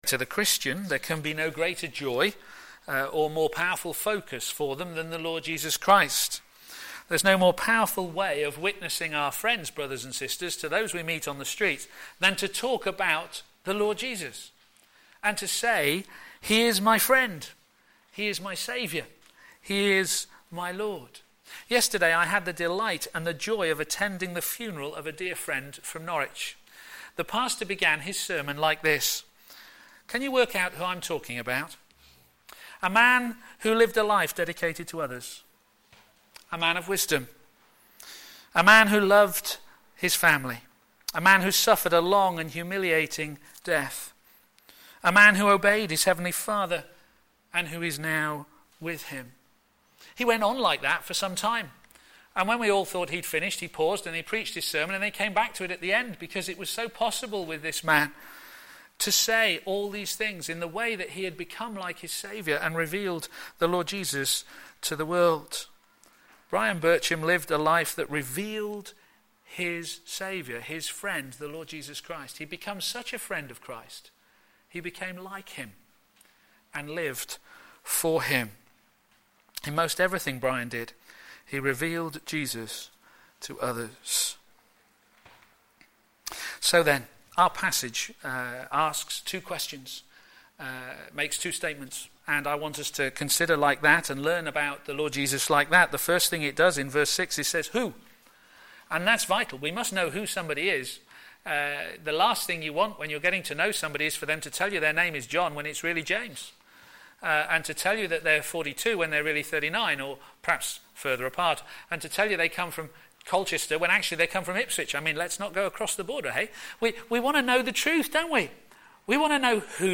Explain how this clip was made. Media Library Media for a.m. Service on Sun 22nd Jun 2014 10:30 Speaker